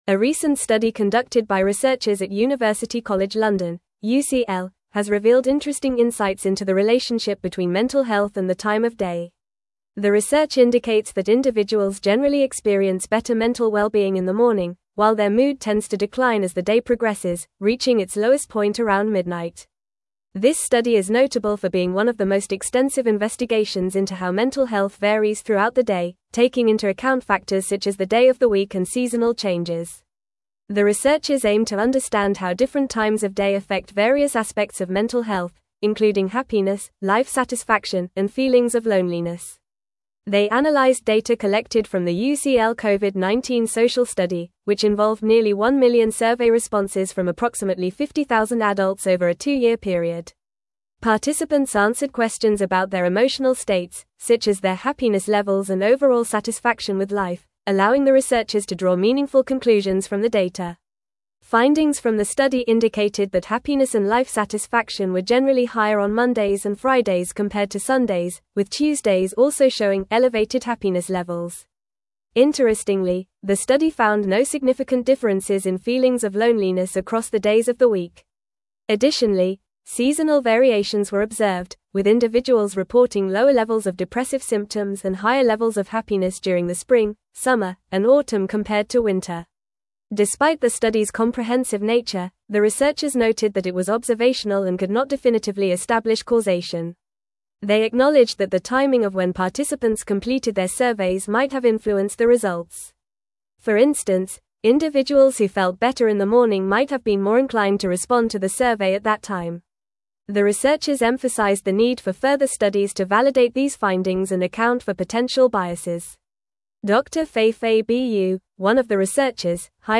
Fast
English-Newsroom-Advanced-FAST-Reading-Mental-Health-Declines-Throughout-the-Day-Study-Finds.mp3